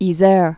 (ē-zâr)